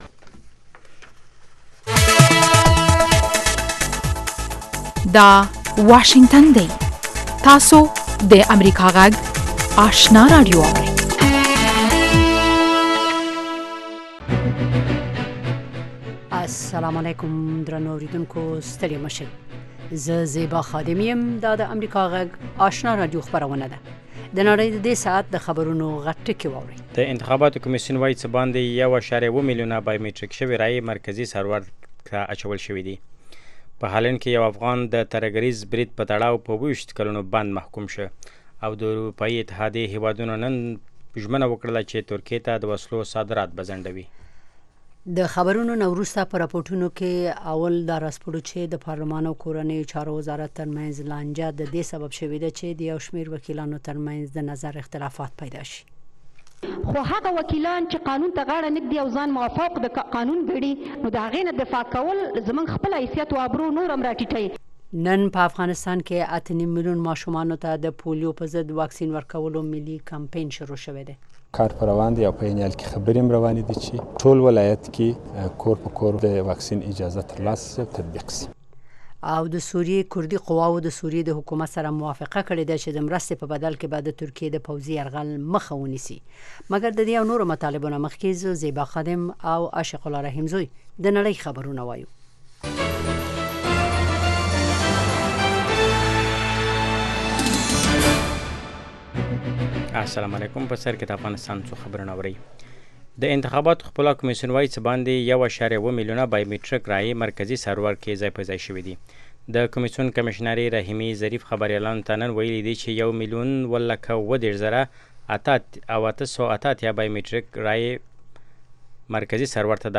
دوهمه ماښامنۍ خبري خپرونه